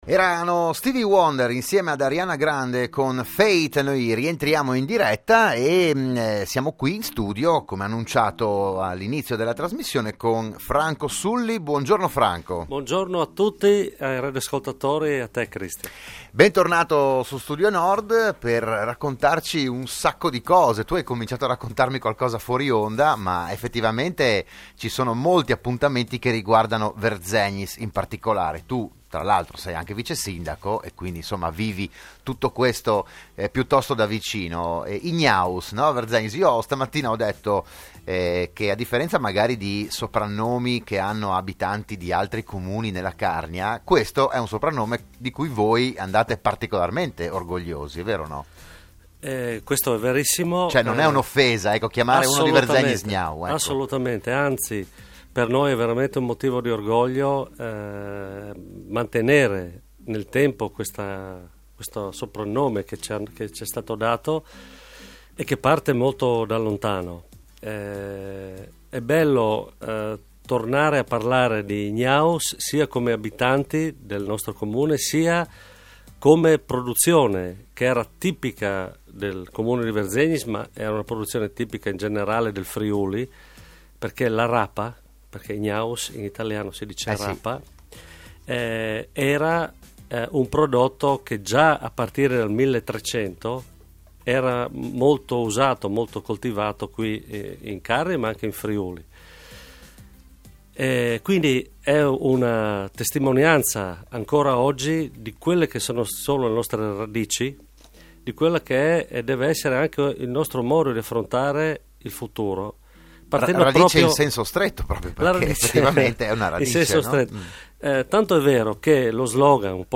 Anche quest'anno l'amministrazione comunale ha proposto un percorso culinario dedicato alle rape. Il PODCAST dell'intervento del vicesindaco Franco Sulli a Radio Studio Nord